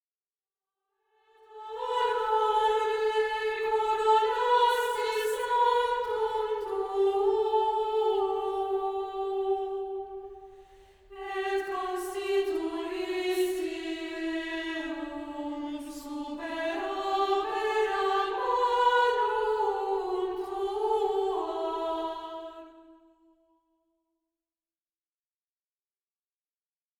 Plain-chant et polyphonies du 14e siècle
Antienne